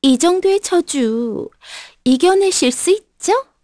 Shamilla-Vox_Skill5_kr_b.wav